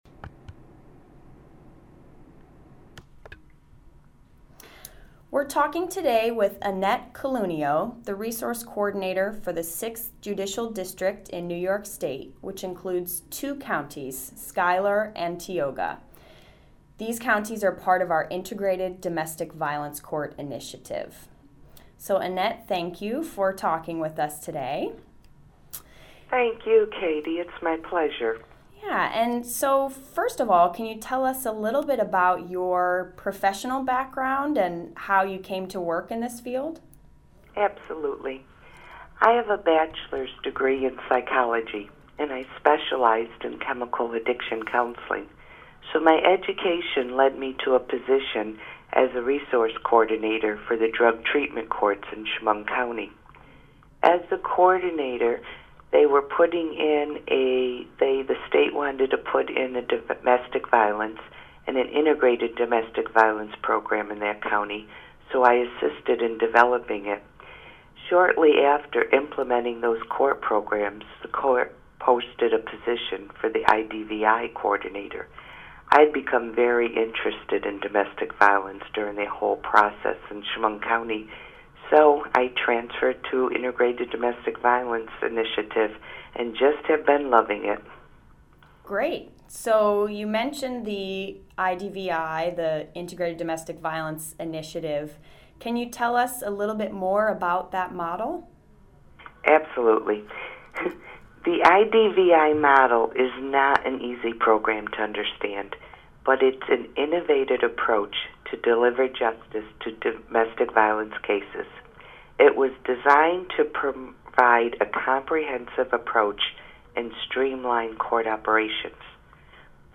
The Role of the Resource Coordinator: A Conversation